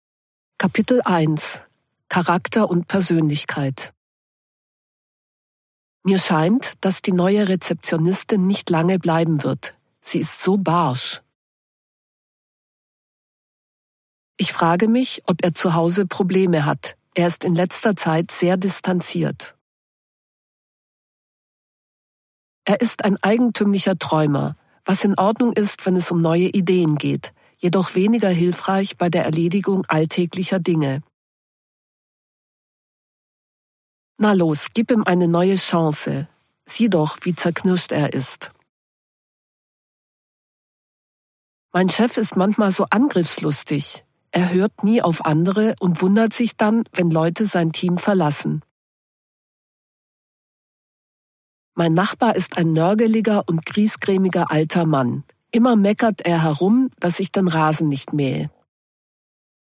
Do książki dołączony jest kurs audio (MP3) z nagraniami native speakerki.